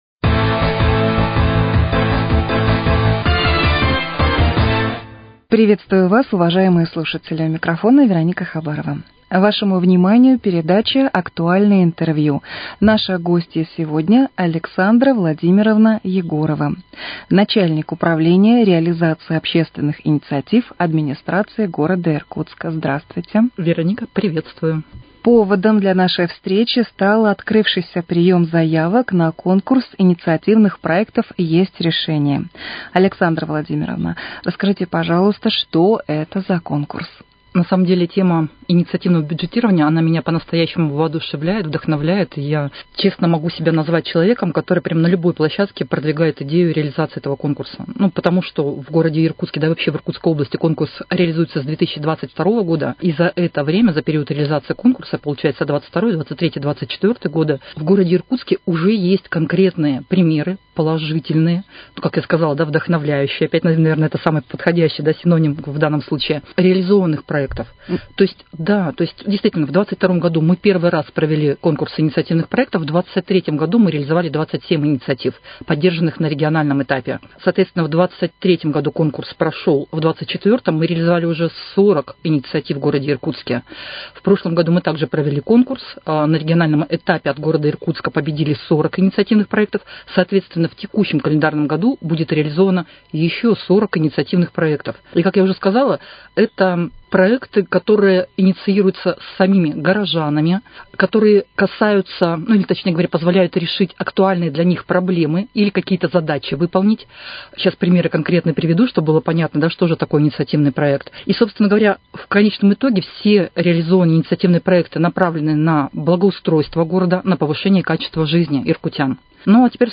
Актуальное интервью: О реализации в Иркутске проекта «Есть решение»